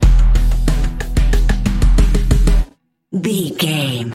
Aeolian/Minor
synthesiser
drum machine
hip hop
Funk
neo soul
acid jazz
energetic
cheerful
bouncy
Triumphant
funky